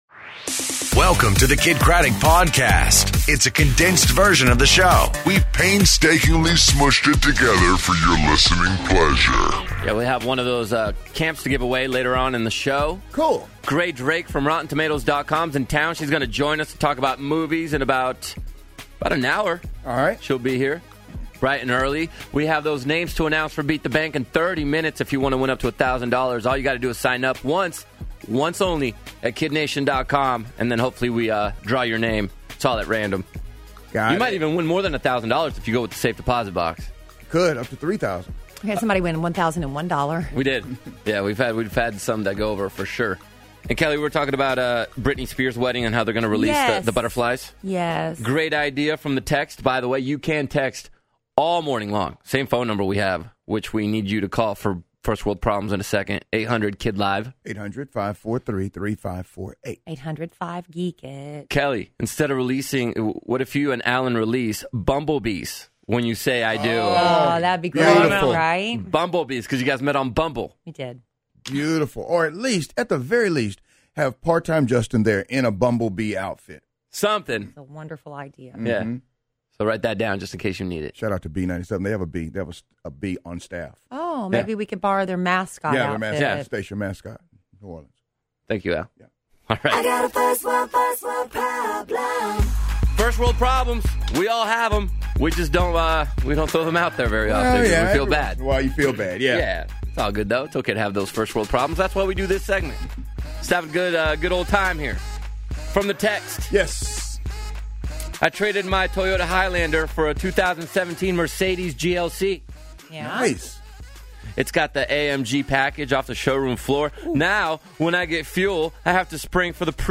In Studio! First World Problems, And New Fashion Technology